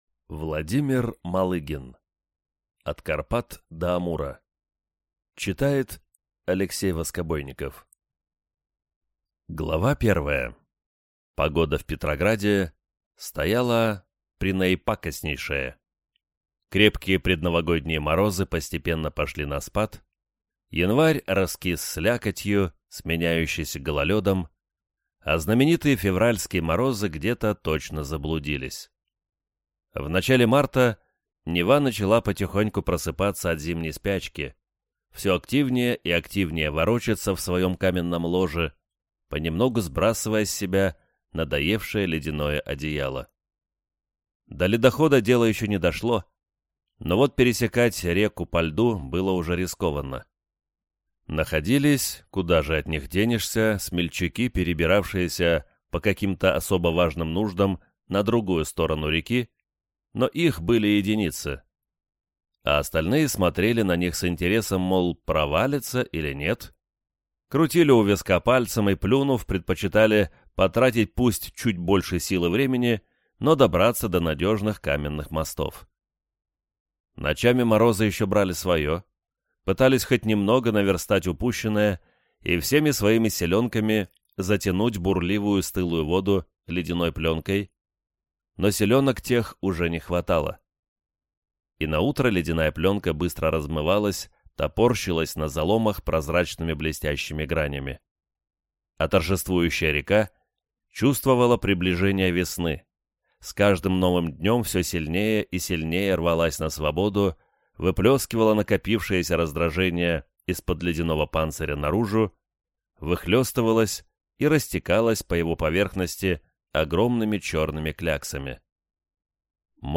Аудиокнига От Карпат до Амура | Библиотека аудиокниг